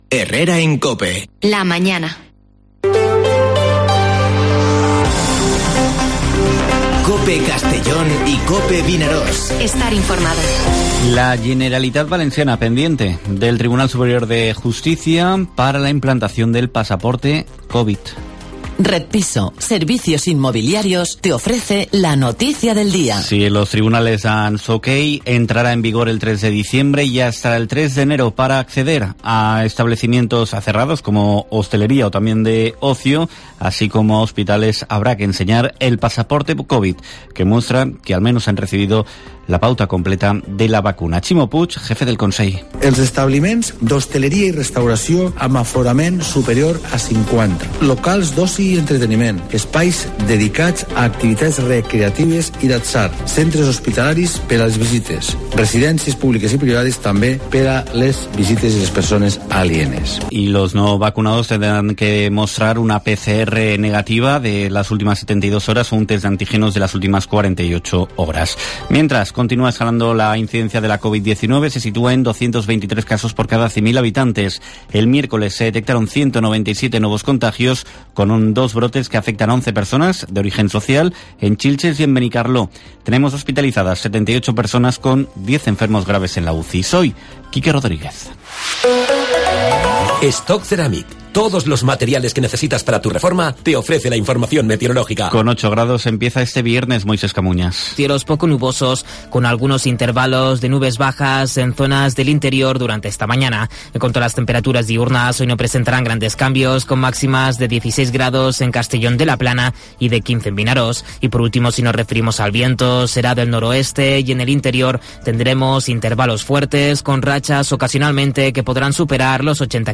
Informativo Herrera en COPE en la provincia de Castellón (26/11/2021)